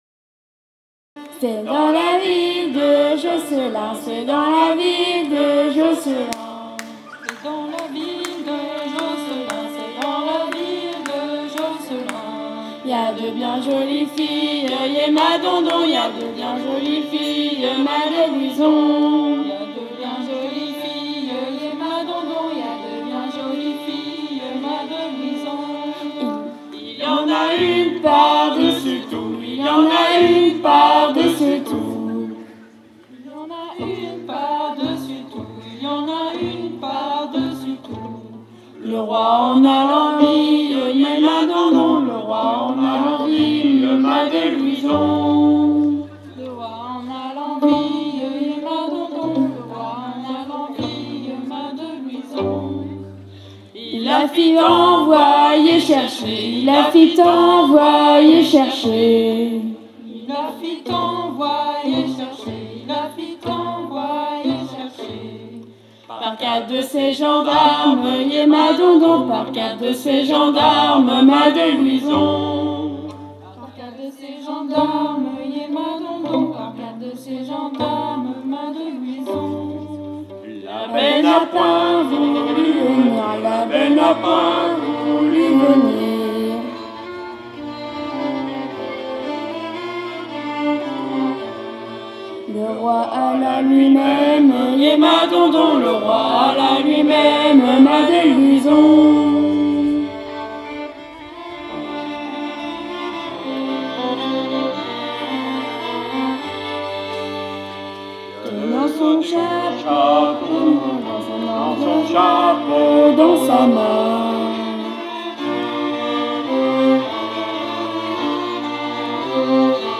05_c_est_dans_la_ville_de_josselin-chant-violons.mp3